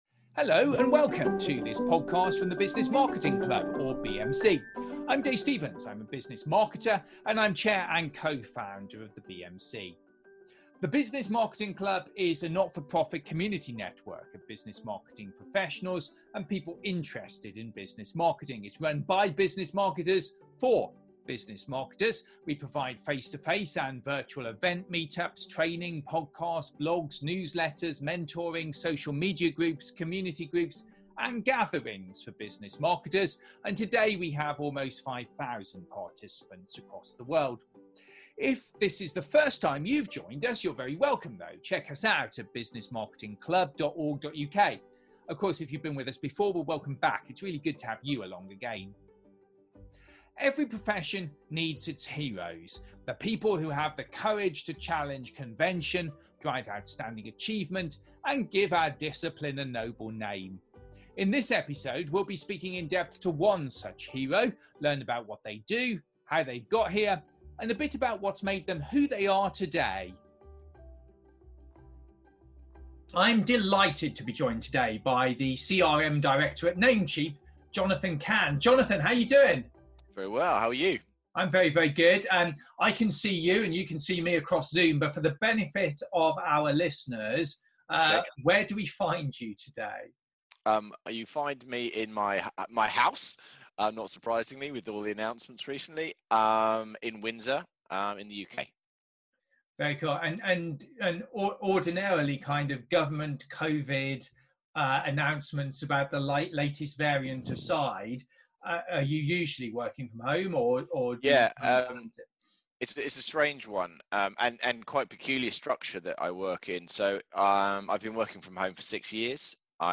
Continuing a series of in-depth interviews with some of Business Marketing’s heroes.